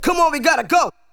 VOX SHORTS-2 0004.wav